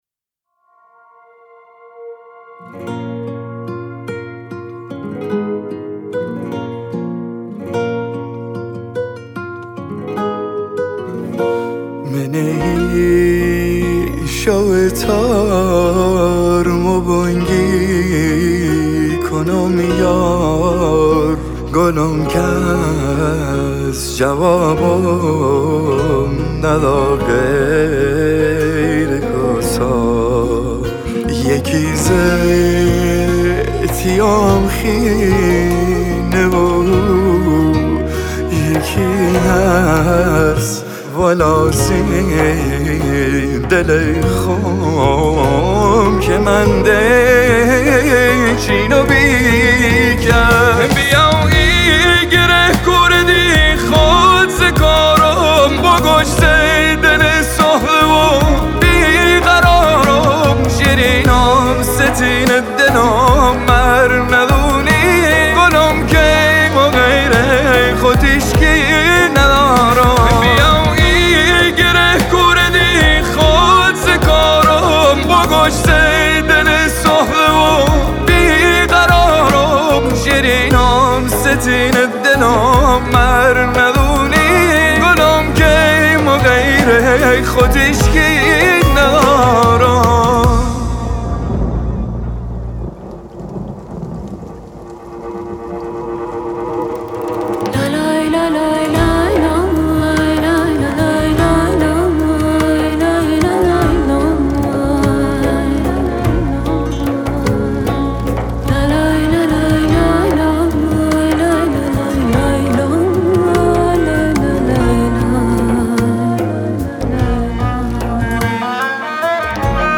دانلود آهنگ بختیاری